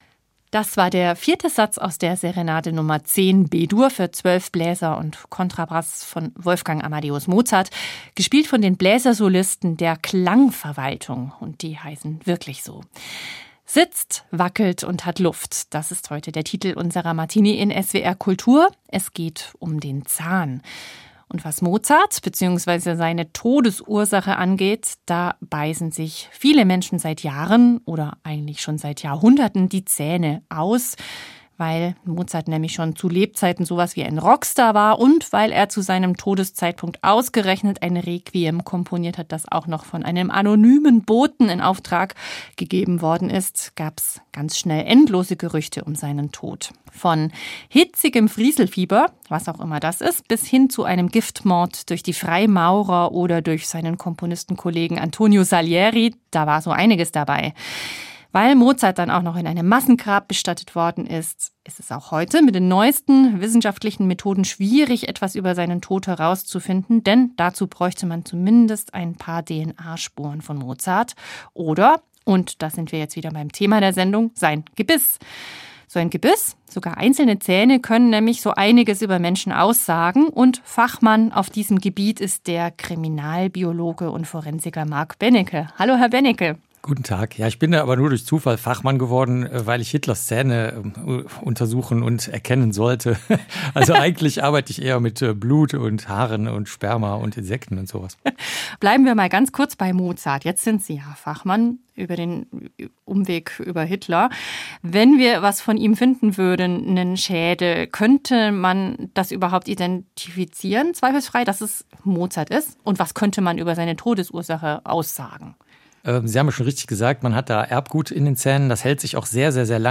Der Kriminalbiologe Mark Benecke über das, was das Kauwerkzeug über vergangene Epochen und Kriminalfälle aussagt. Interview